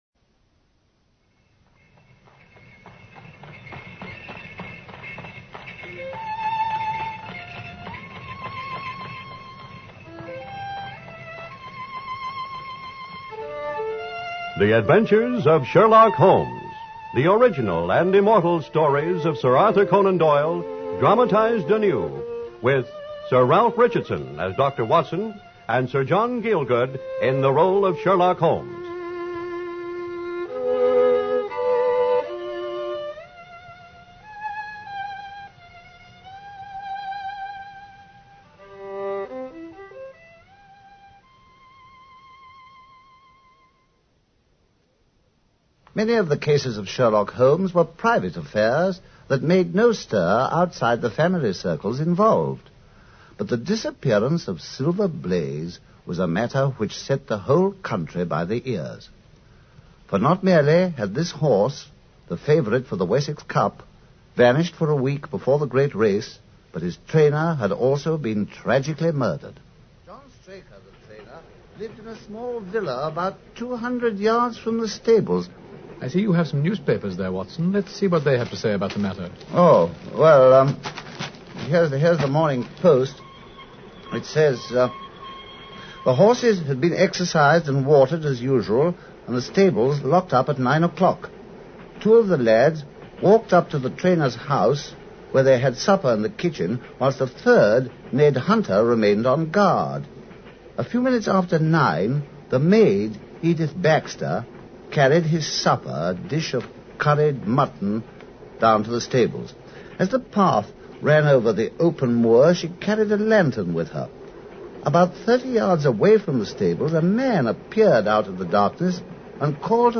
Radio Show Drama with Sherlock Holmes - The Disappearance Of Silver Blaze 1954